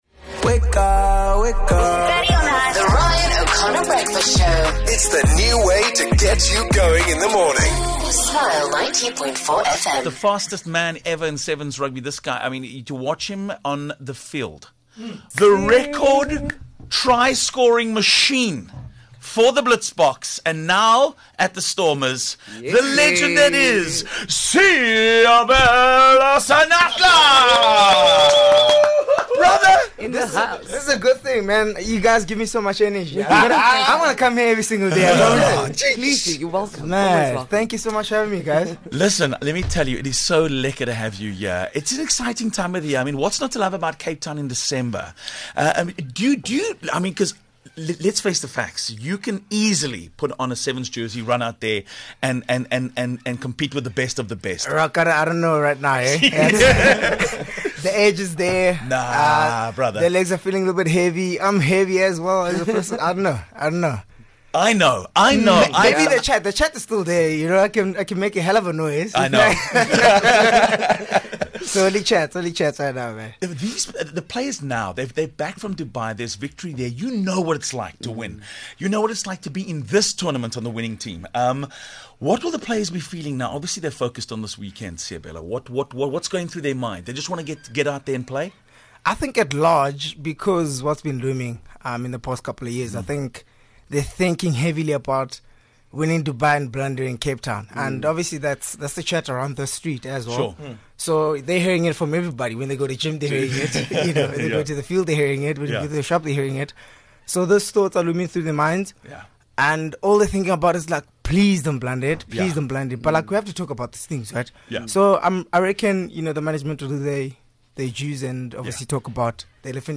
What a wonderful morning having DHL Stormers speedster and former Blitzboks Olympian medalist Seabelo Senatla in studio talking all things Cape Town Sevens.